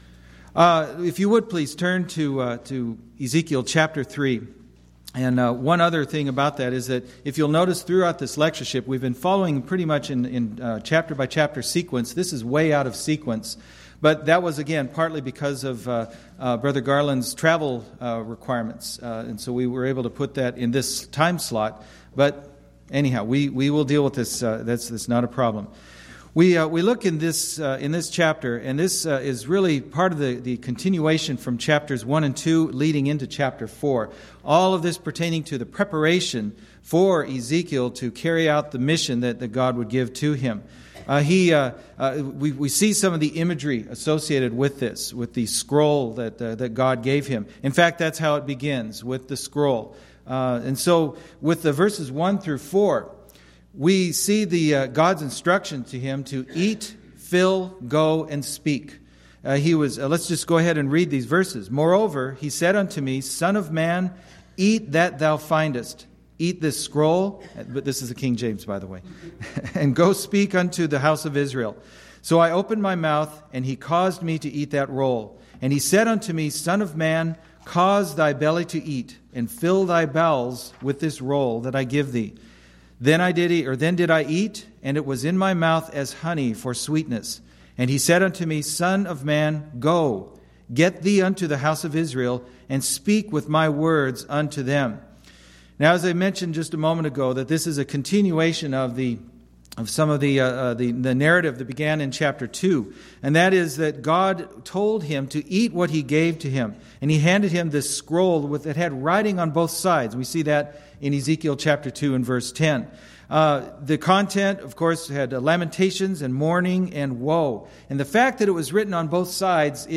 Event: 10th Annual Schertz Lectures
this lecture